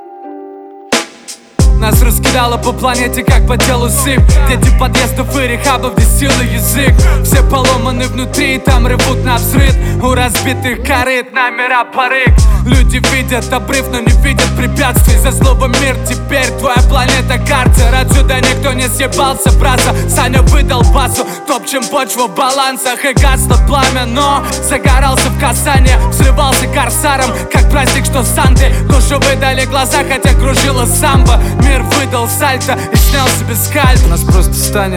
Жанр: Русские
# Рэп